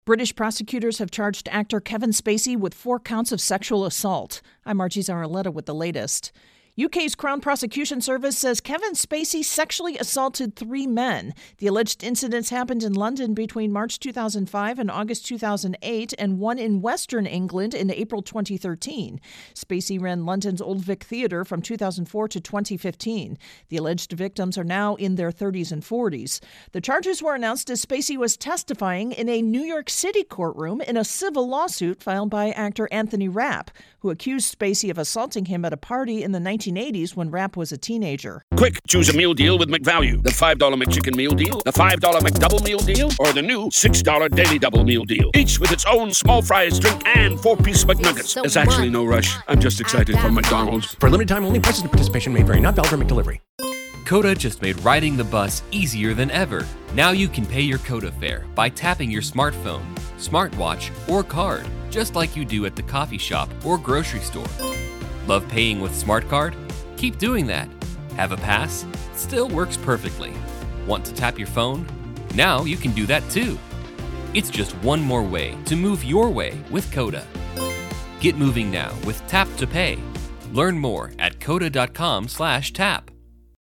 intro & voicer for Kevin Spacey